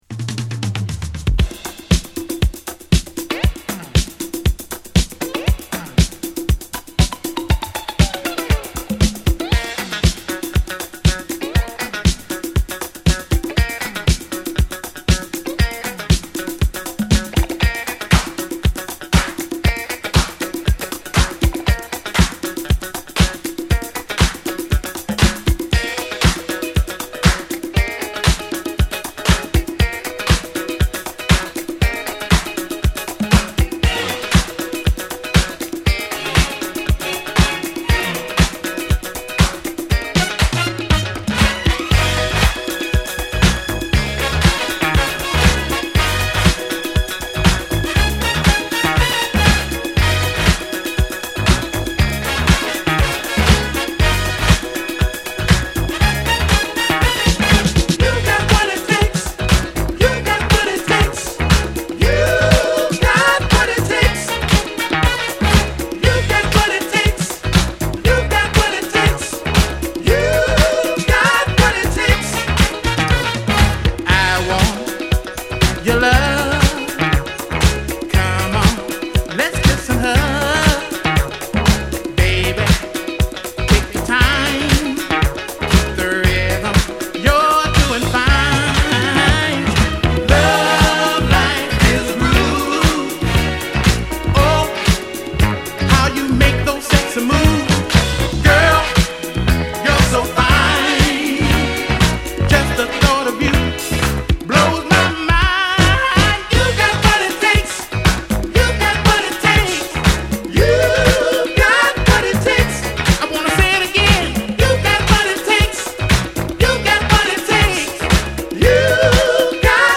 軽快なファンク・ディスコチューン